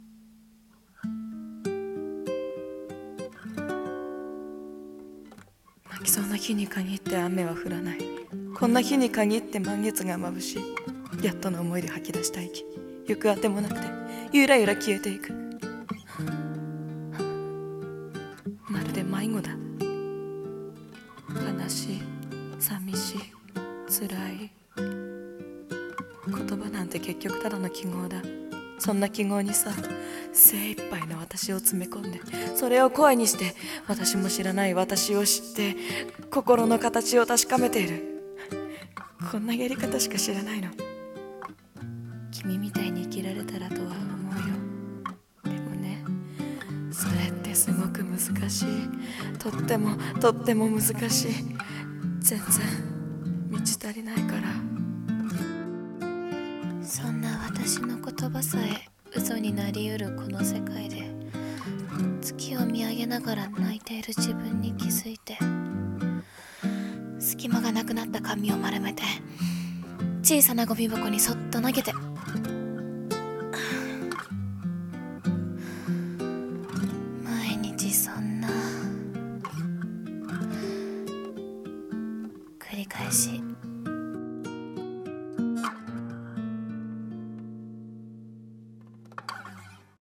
【朗読】満ちるには、まだ。